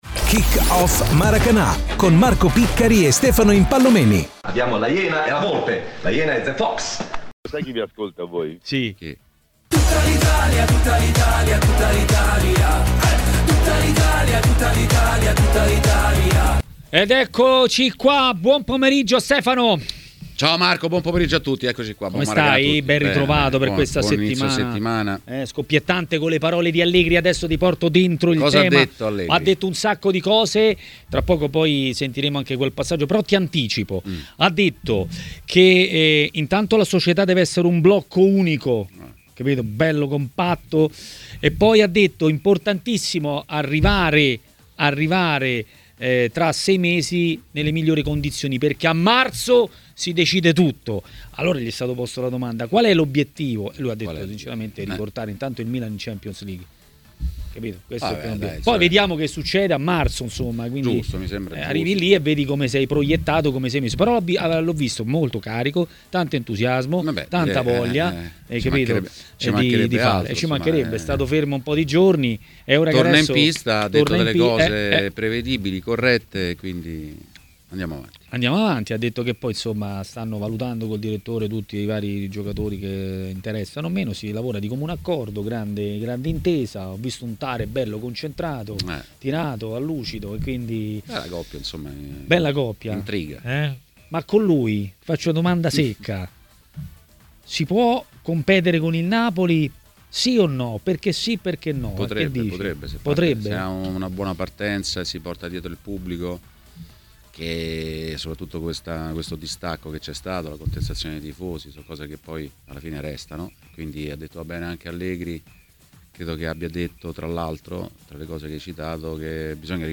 Il giornalista